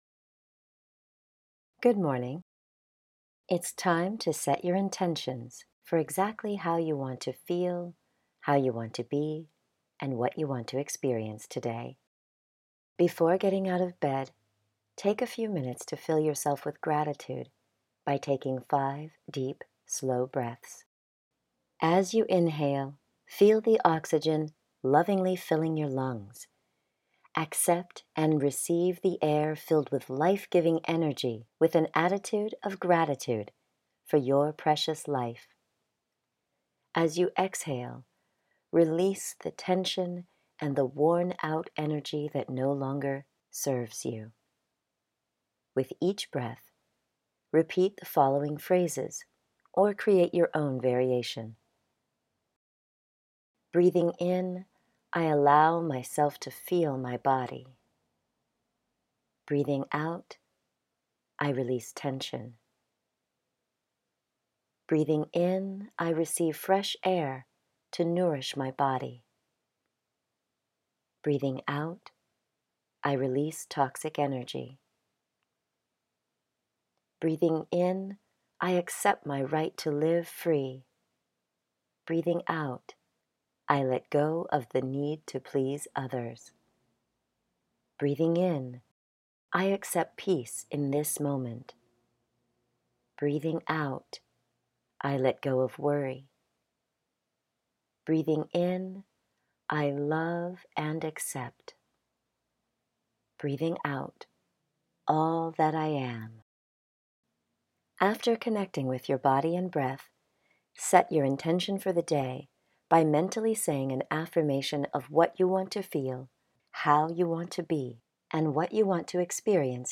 Morning Intentions Guided Meditation